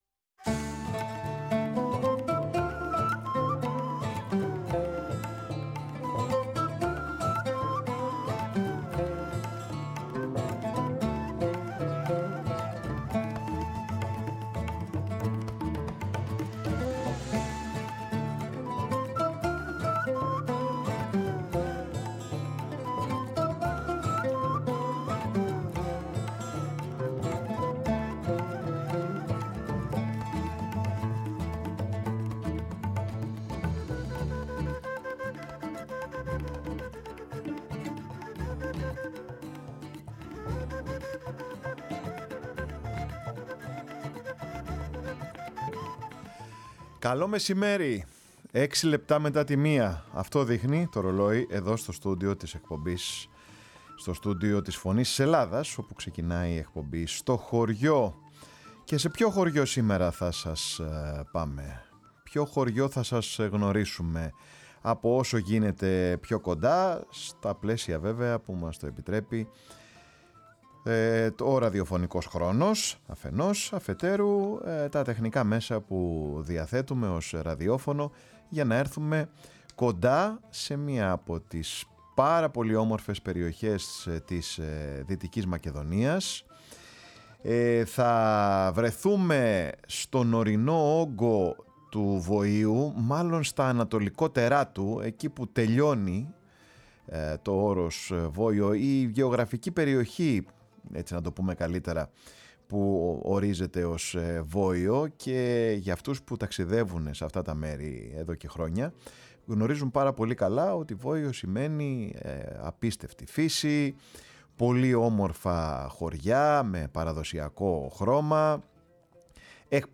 Τη Βλάστη Κοζάνης και το γειτονικό Σισάνι, δυο πανέμορφα χωριά της Δυτικής Μακεδονίας, γνωρίσαμε με την εκπομπή “Στο Χωριό”, στη Φωνή της Ελλάδας, το μεσημέρι της Τρίτης, 24 Φεβρουαρίου 2026.